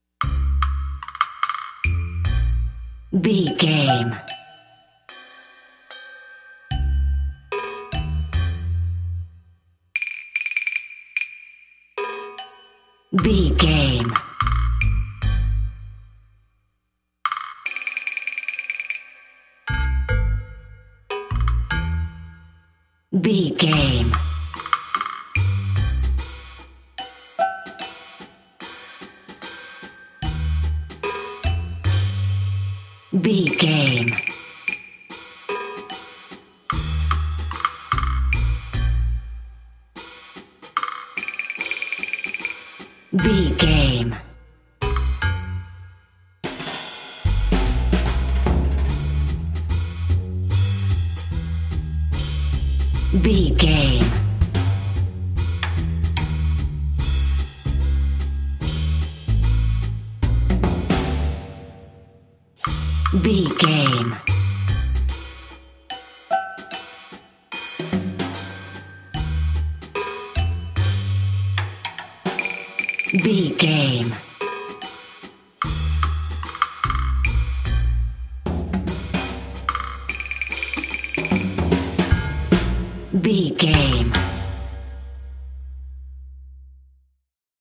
Ionian/Major
B♭
drums
percussion
double bass
silly
circus
goofy
comical
cheerful
perky
Light hearted
secretive
quirky